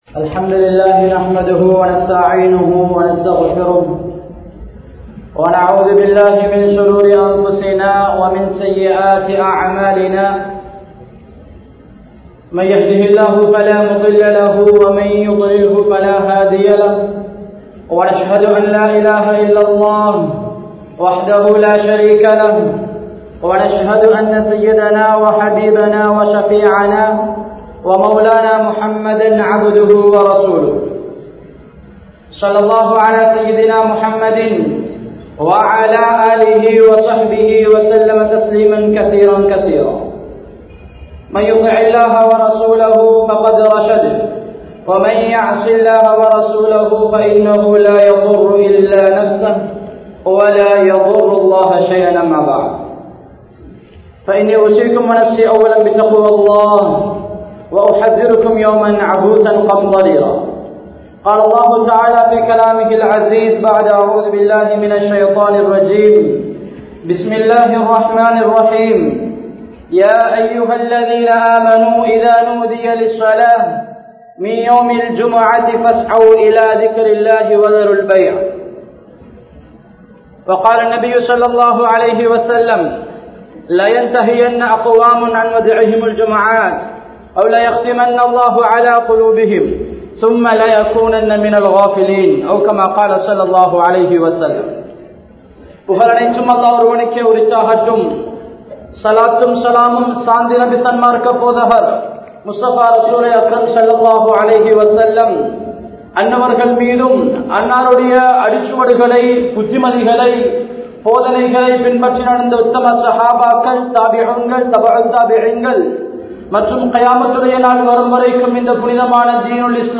Jumuavai Ganniyapaduththungal (ஜூம்ஆவை கண்ணியப்படுத்துங்கள்) | Audio Bayans | All Ceylon Muslim Youth Community | Addalaichenai
Matale, Gongawela Jumua Masjidh